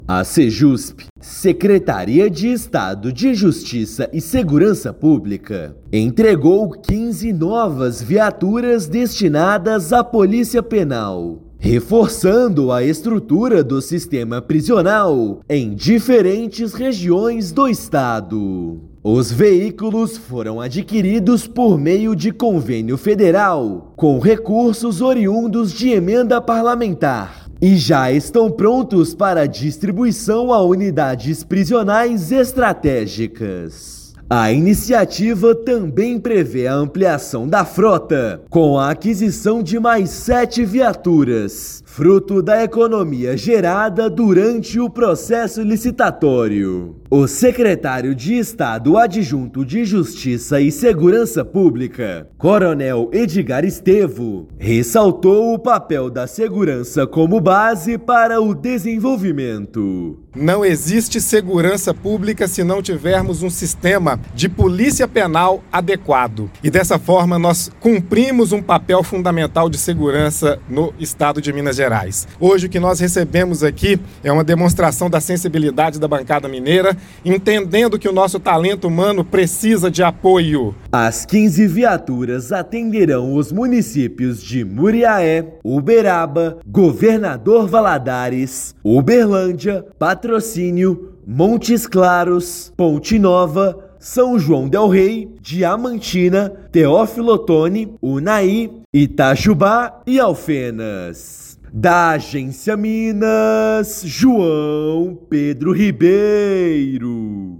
Estado tem ampliado estrutura do sistema prisional e fortalecido atuação em todas as regiões. Ouça matéria de rádio.